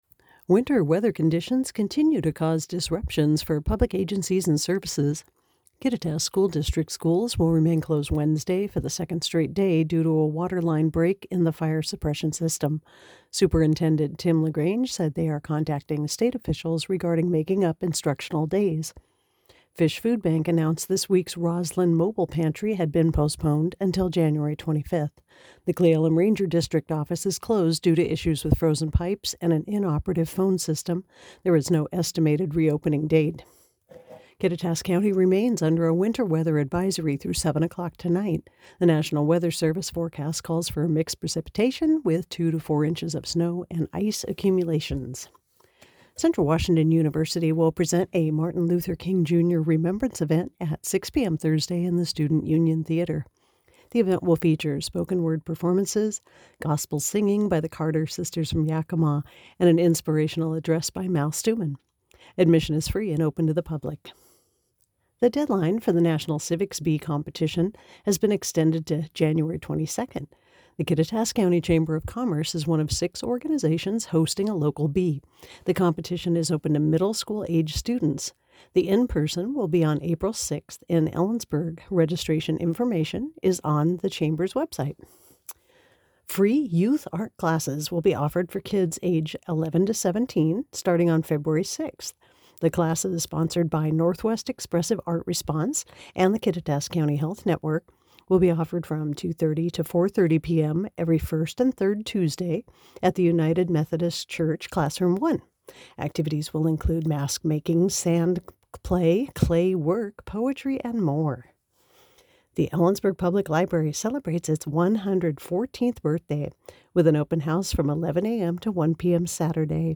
Click here to listen to today's newscast